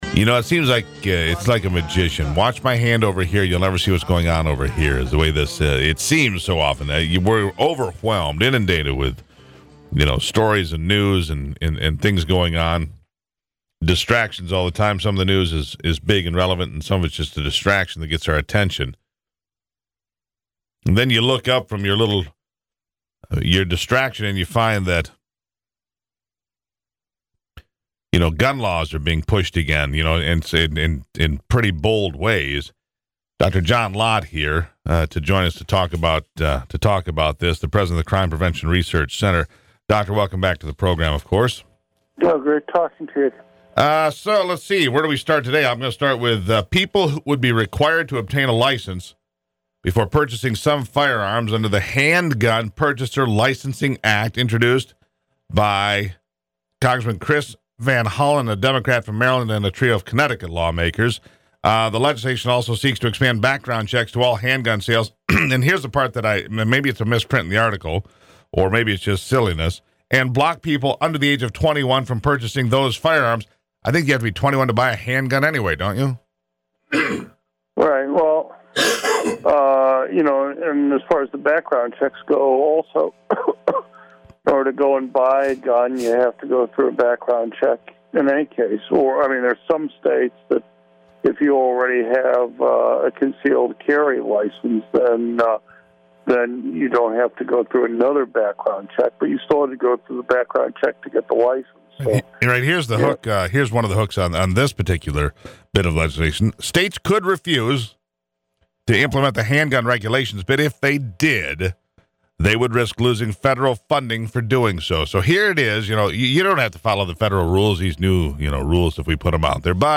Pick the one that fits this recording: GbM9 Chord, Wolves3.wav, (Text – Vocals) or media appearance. media appearance